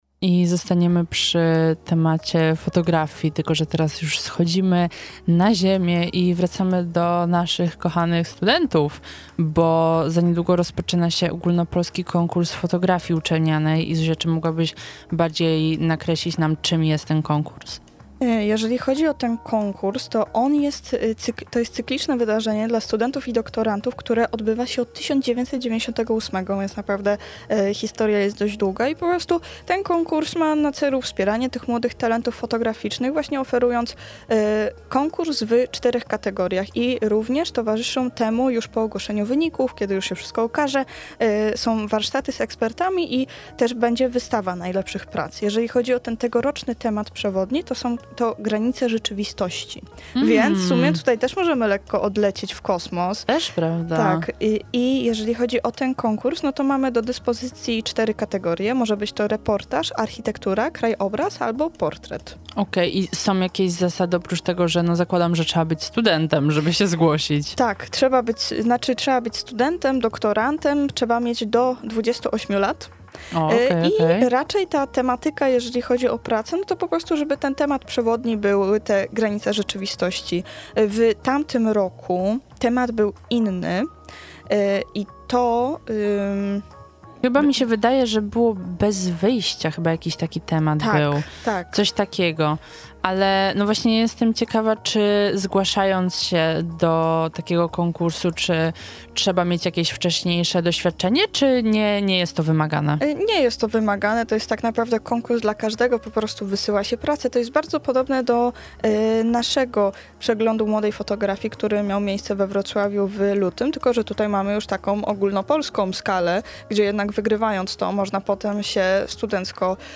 Rozmowa odbyła się w ramach środowej Pełnej Kultury.